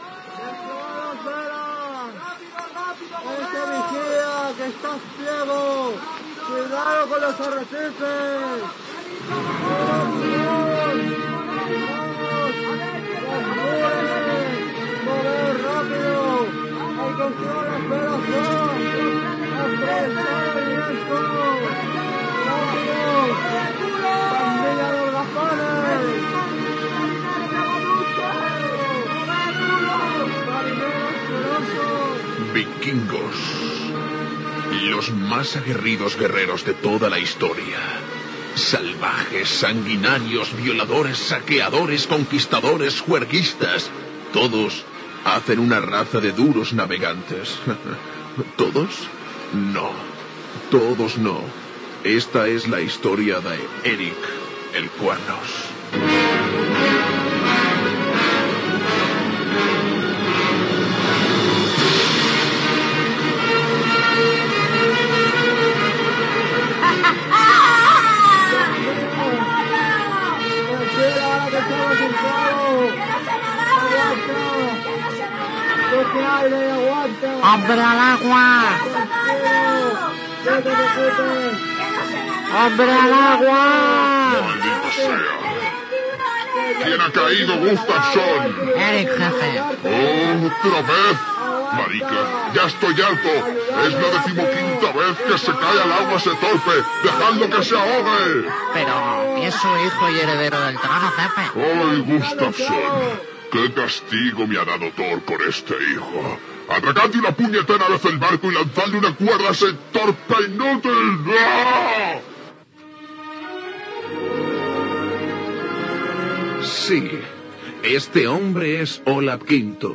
Historieta radiofònica